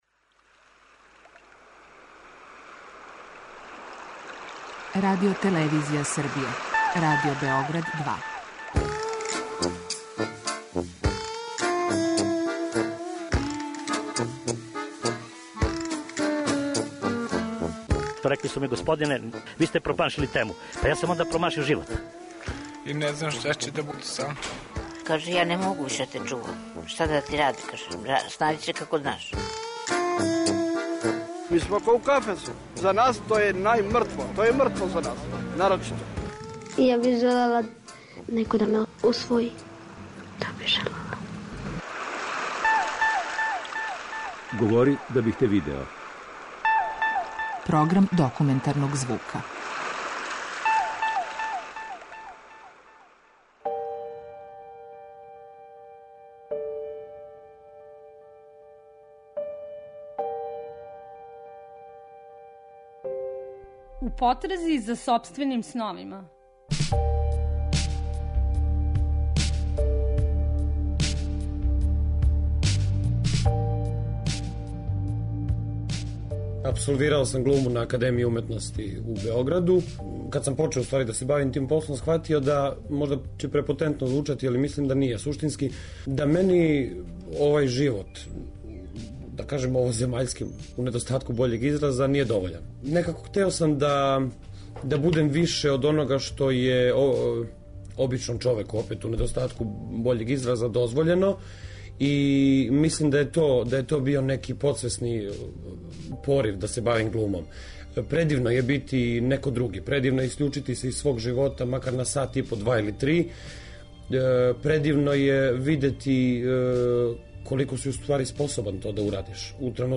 Dokumentarni program